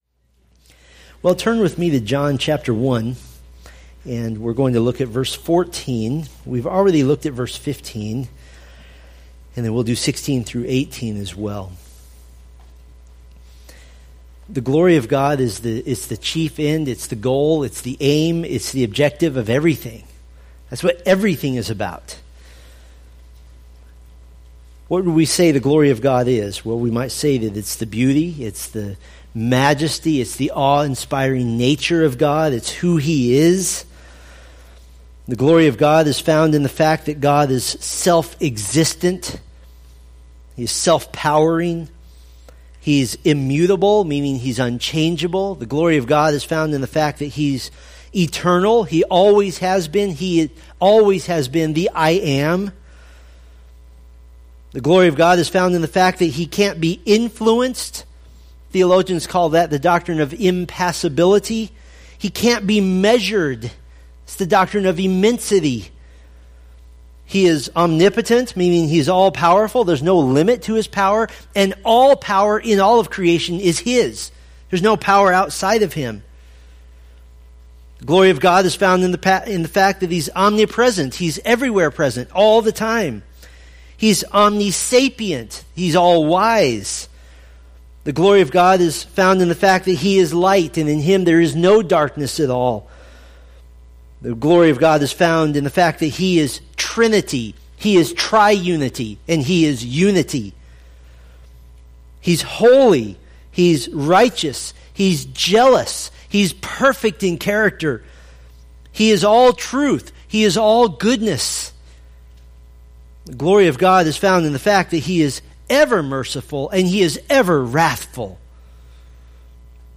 Preached October 23, 2016 from John 1:14,16-18